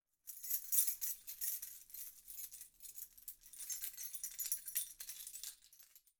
EinSchlüsselbund der geschüttelt wird. Man hört das aufeinanderschellen der Schlüssel ganz deutlich.